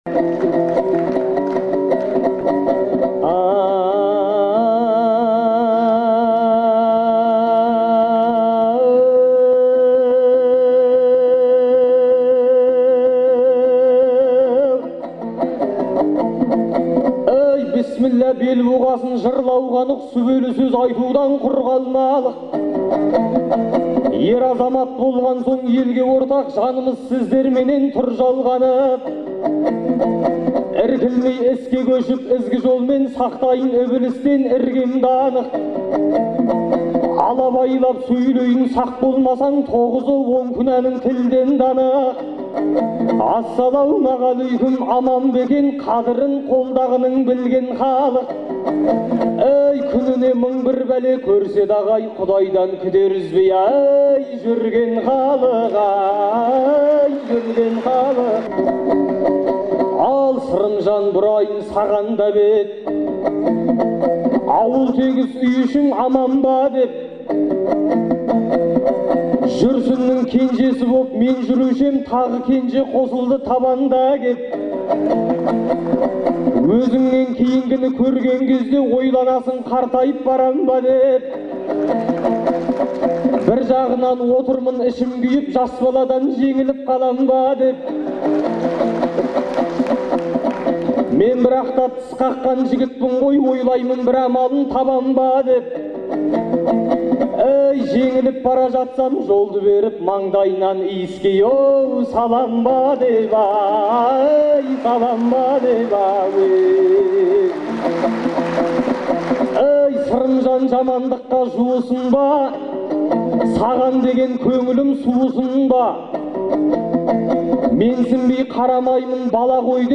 Мамырдың 2-сі күні Алматыда өткен "Төртеу түгел болса" деген айтыстың үшінші жұбы